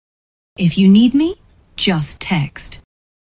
女性の発言（クリックして音声を聞いて下さい）に対する返答として最もふさわしいものを、選択肢から選んでください。